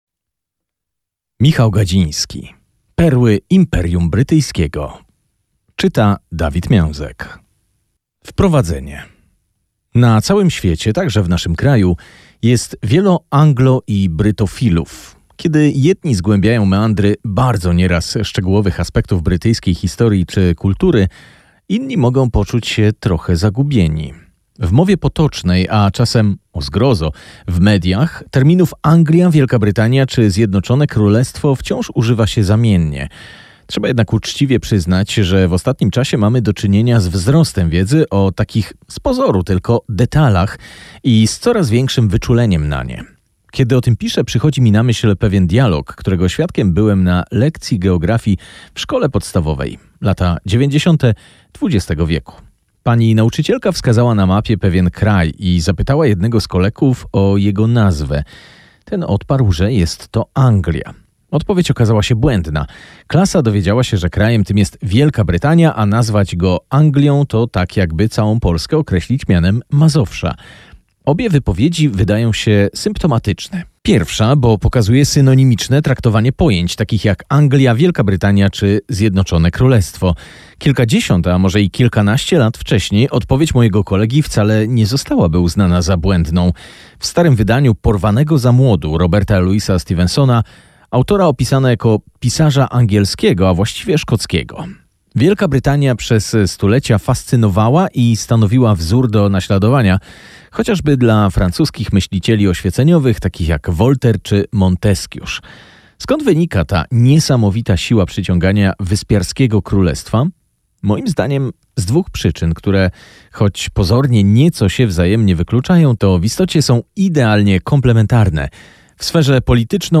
Posłuchaj fragmentu książki: MP3